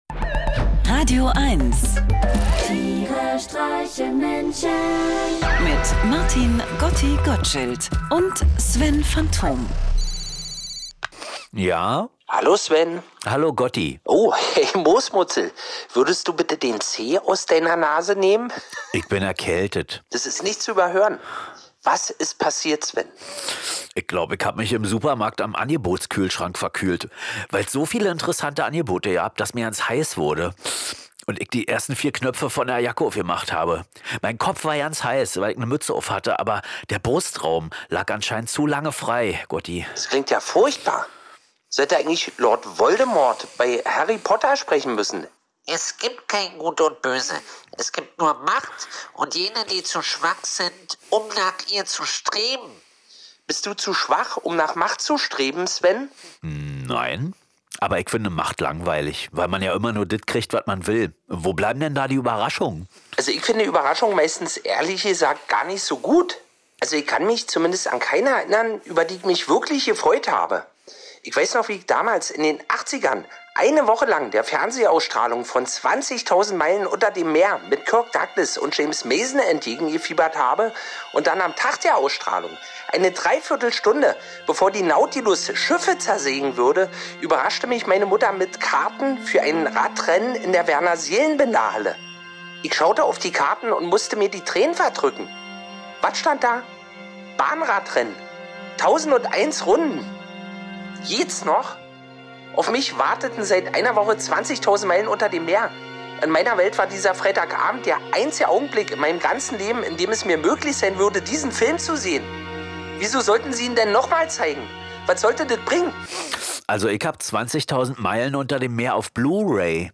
Einer liest, einer singt und dabei entstehen absurde, urkomische, aber auch melancholische Momente.
Comedy